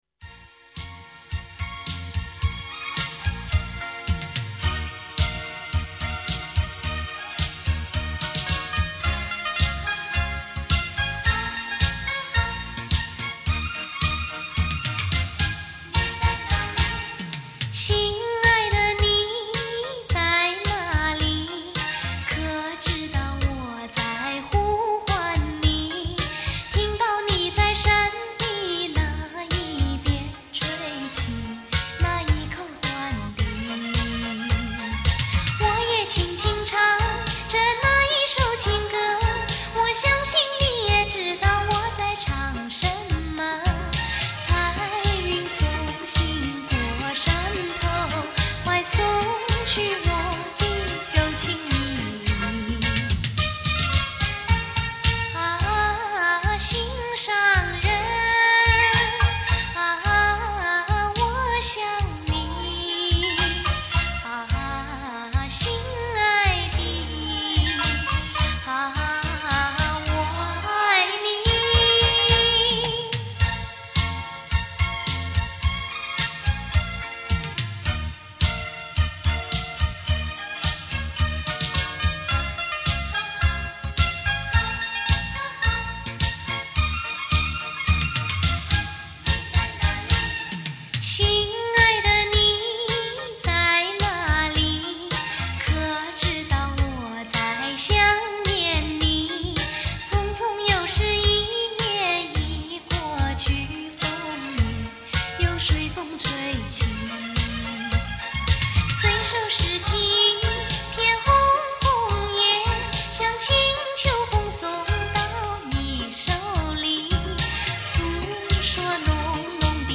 甜歌歌手
曲风如水，带着江南少女灵秀婉转的情致。
很好听的女声，谢谢啊